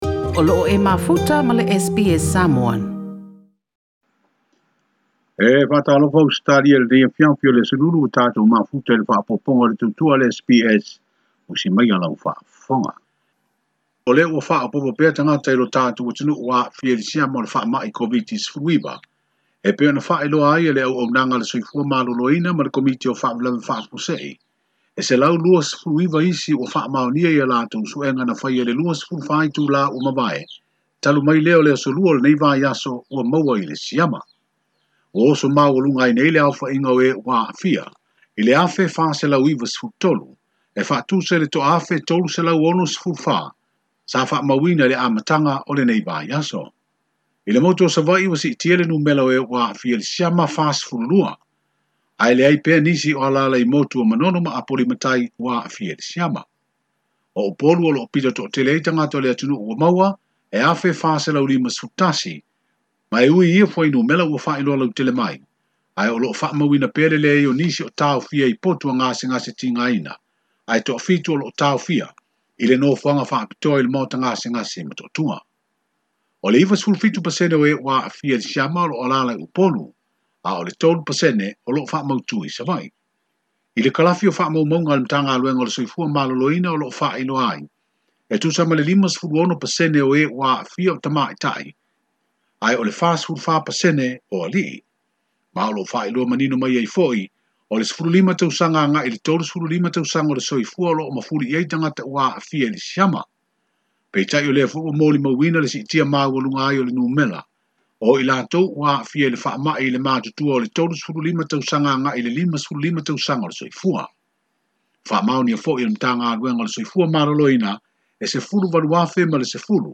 Ripoti mai Apia